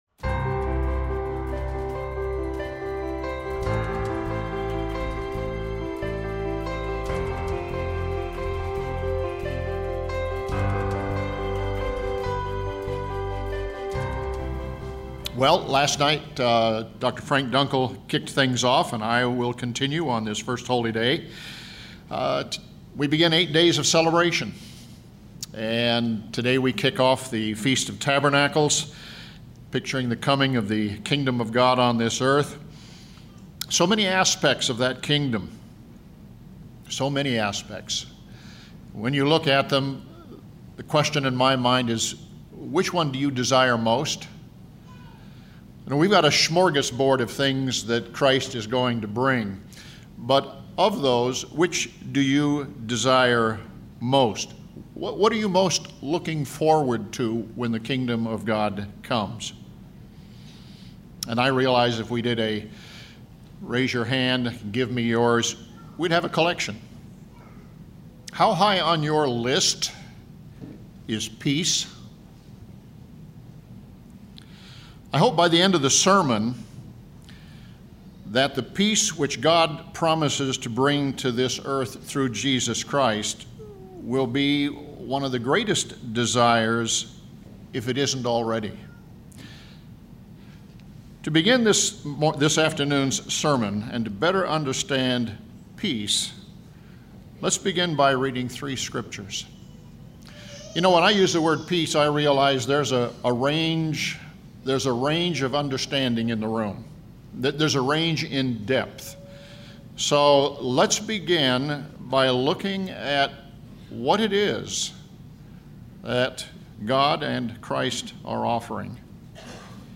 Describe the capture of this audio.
This sermon was given at the Lake Junaluska, North Carolina 2017 Feast site.